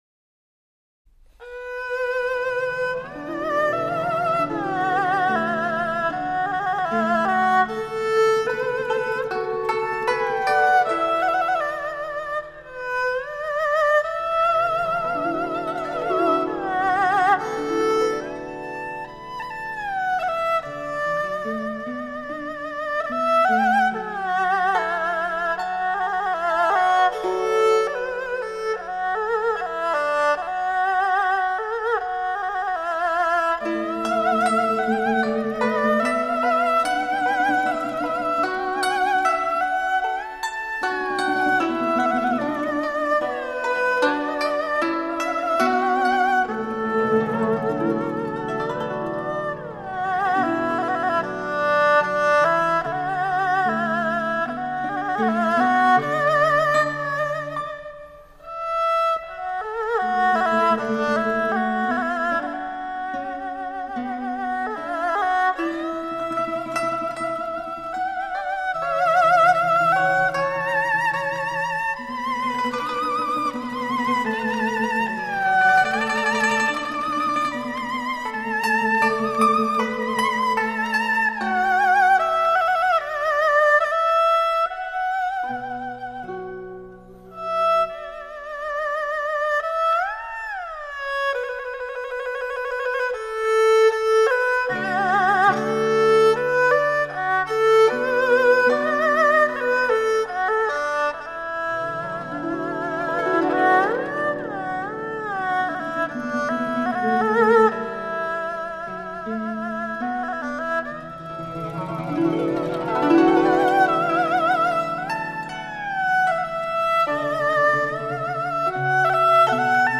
二胡
古筝
中阮